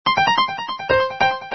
SAMPLES : Piano
piano nē 61
piano61.mp3